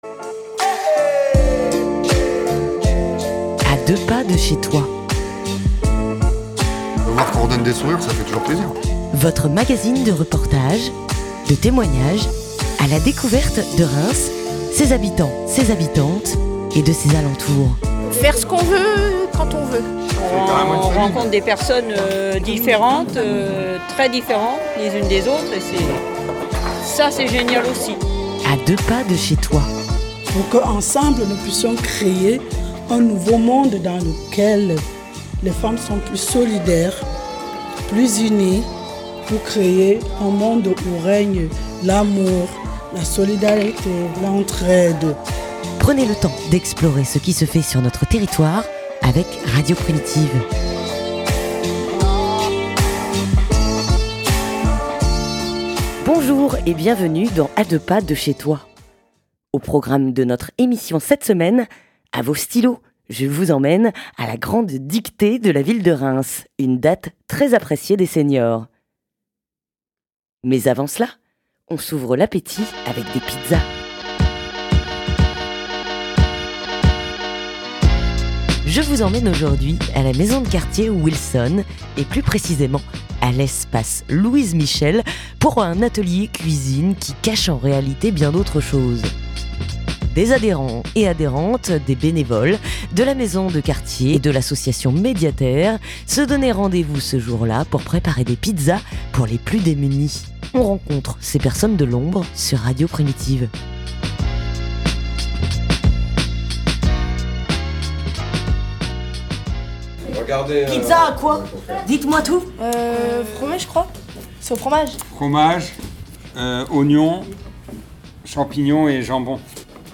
- A la Maison de Quartier Wilson, espace Louise Michel pour un atelier cuisine qui cache en réalité autre chose ! Des adhérents et bénévoles de la structure et de l’association Médiaterre se donnaient rendez-vous ce jour là pour préparer des pizzas pour les plus démunis.